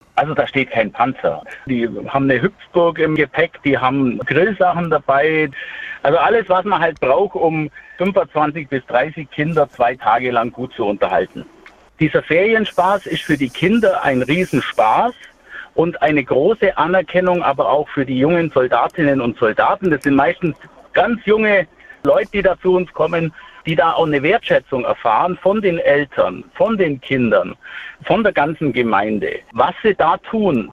So hat sich Michael Obst im SWR-Interview geäußert: